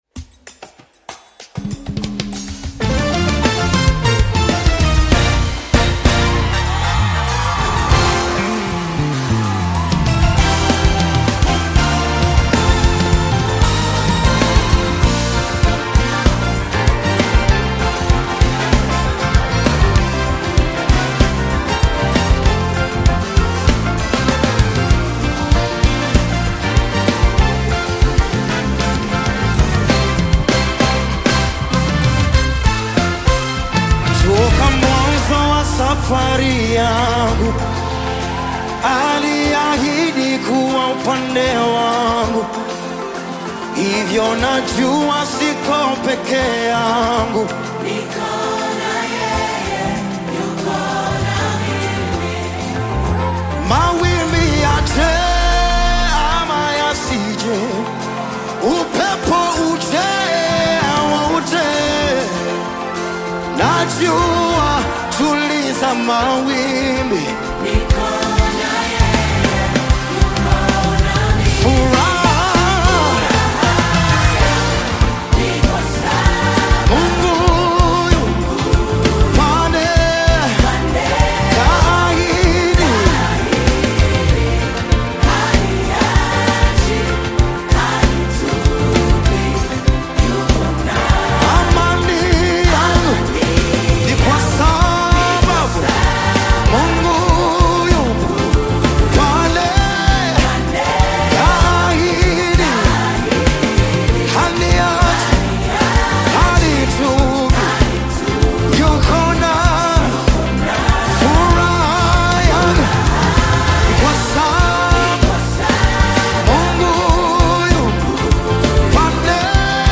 Tanzanian gospel
inspiring worship song
Known for his powerful vocals and faith-driven message